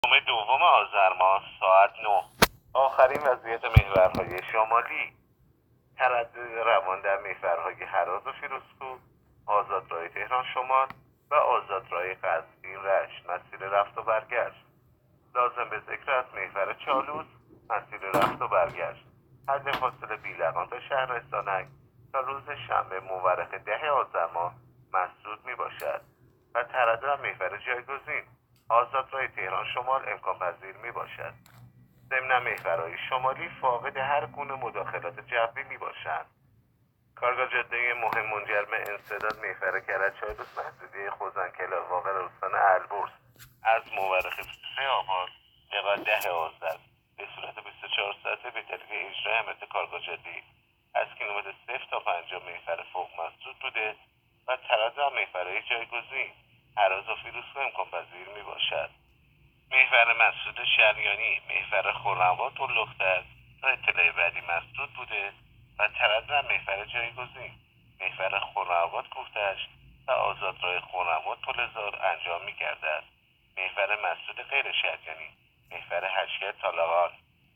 گزارش رادیو اینترنتی از آخرین وضعیت ترافیکی جاده‌ها تا ساعت ۹ دوم آذر؛